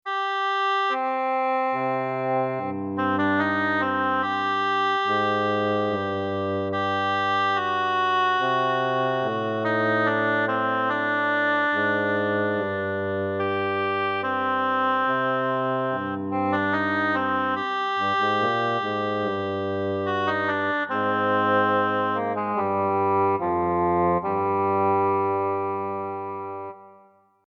ambiente cuento melodía música sintonía